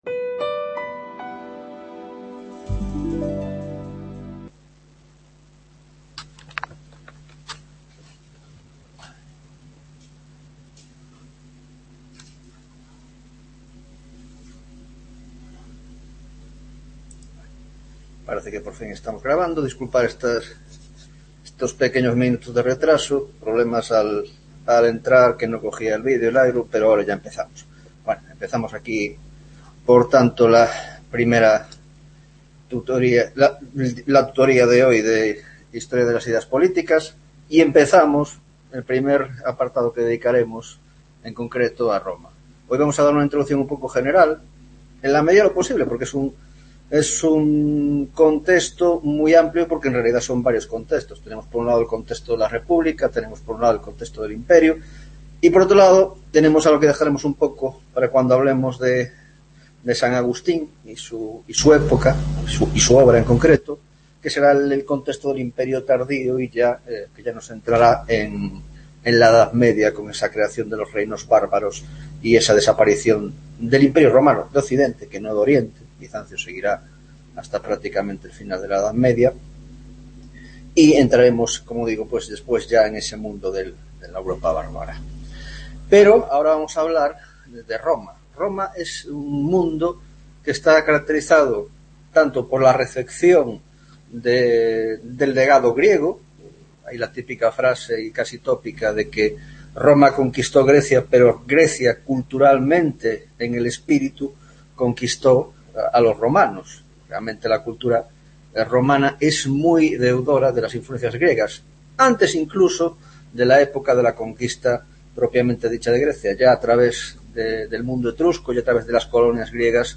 6ª Tutoria de Historia de las Ideas Políticas